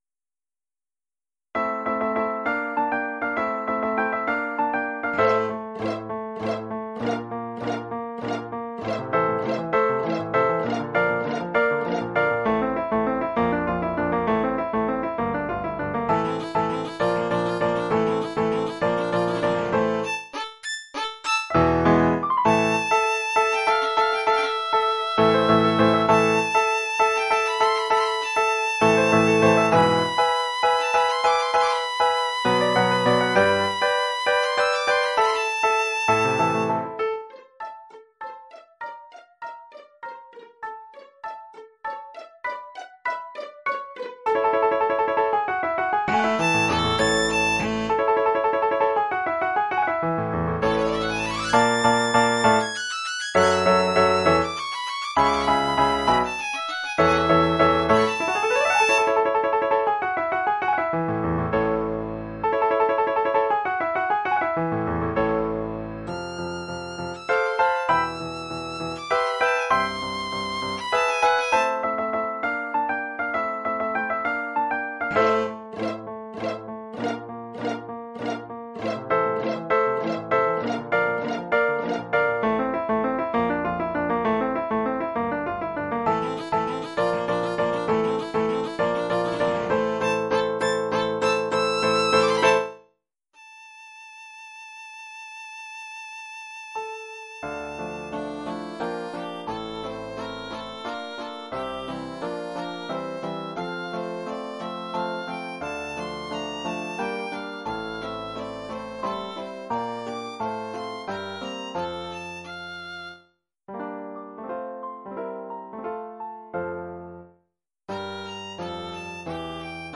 Formule instrumentale : Violon et piano
Oeuvre pour violon et piano.